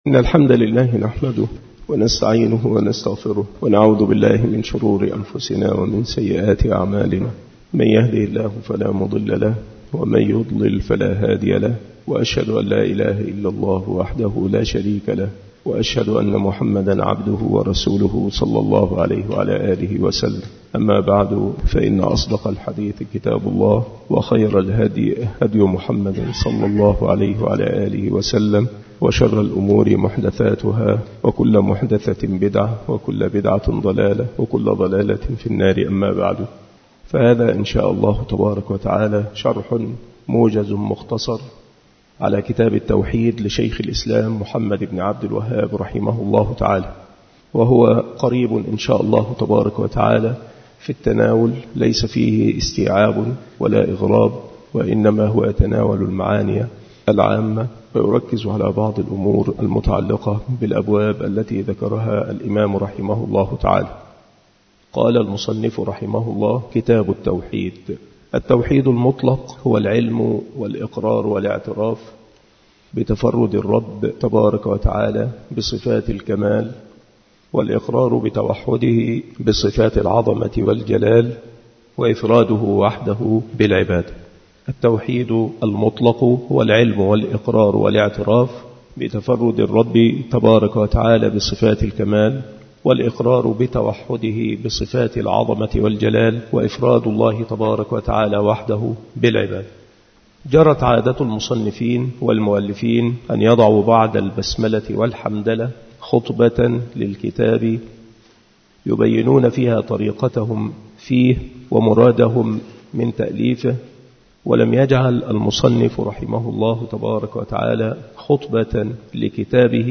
مكان إلقاء هذه المحاضرة بالمسجد الشرقي بسبك الأحد - أشمون - محافظة المنوفية - مصر عناصر المحاضرة : التوحيد المطلق. أقسام التوحيد. العبادة لغةً وشرعًا. ما هو الطاغوت؟